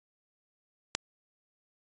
For testing the response of a filter, it can be useful to generate “white noise” as a single high amplitude sample on an otherwise silent track, like this: